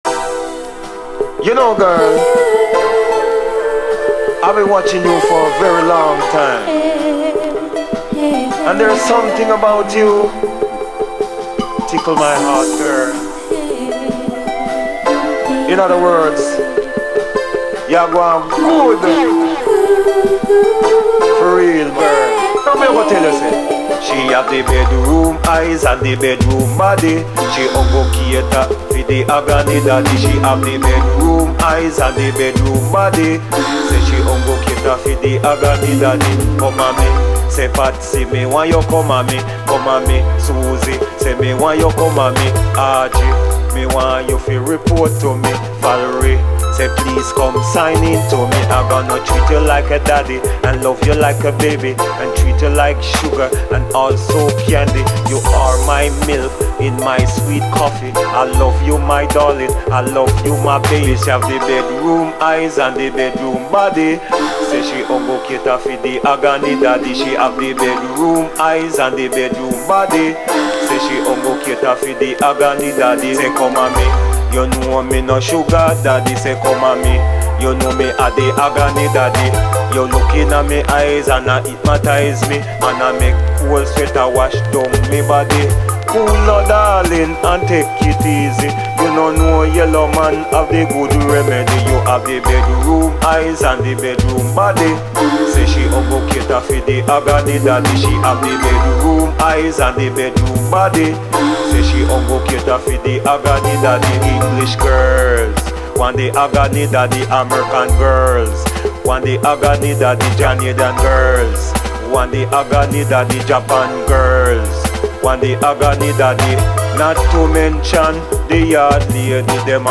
REGGAE
このユッタリと甘く鬼メロウな感じがタマンナイです。